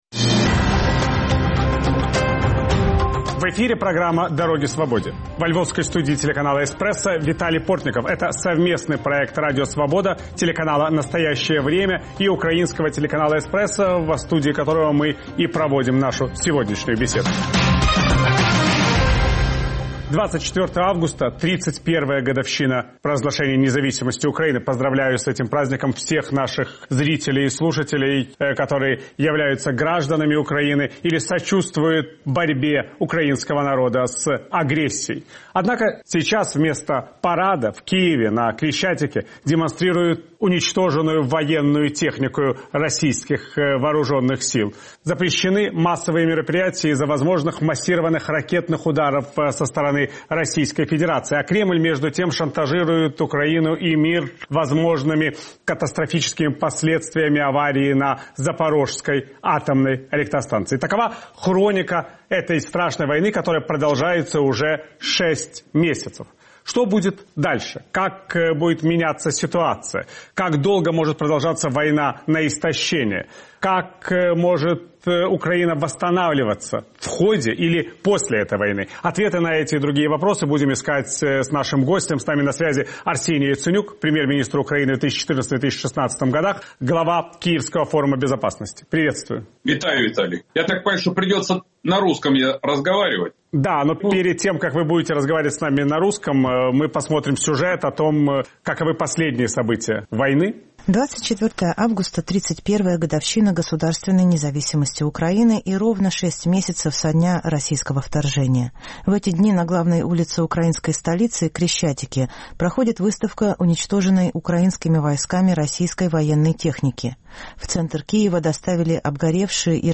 Виталий Портников расспрашивает главу украинского "постмайданного" правительства, бывшего премьер-министра Украины Арсения Яценюка о начале войны в 2014 году, намерениях Владимира Путина и перспективах восстановления Украины.